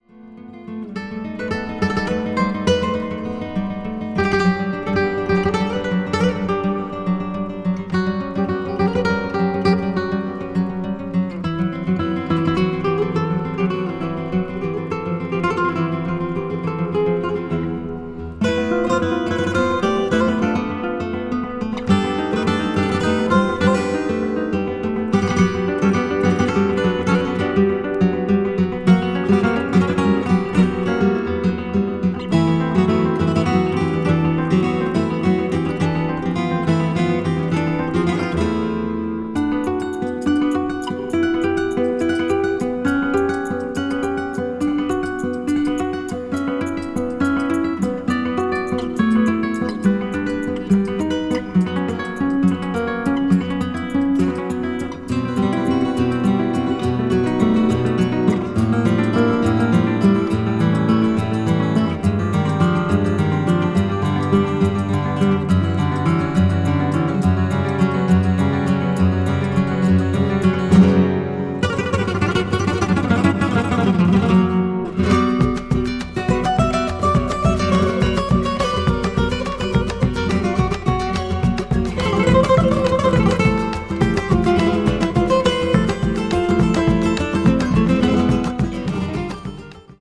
儚くも美しく情熱的なギターの旋律が交錯するB2も最高です。